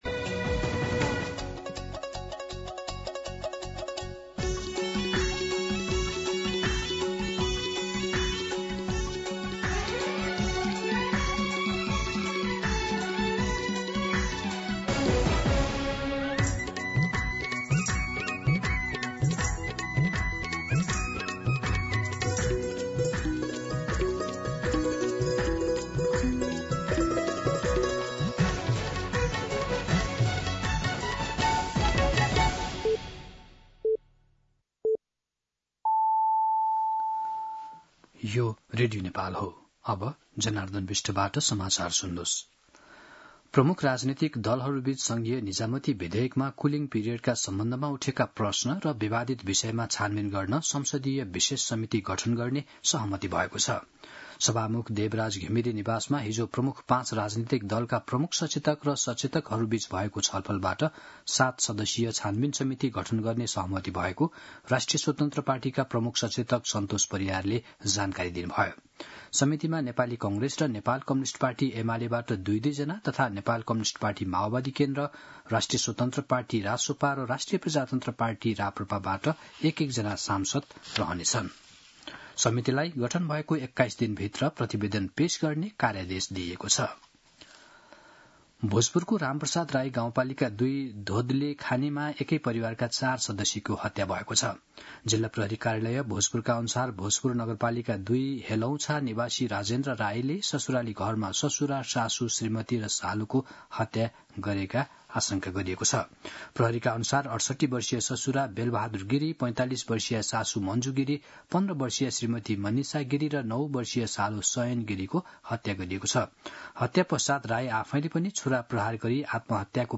मध्यान्ह १२ बजेको नेपाली समाचार : २२ असार , २०८२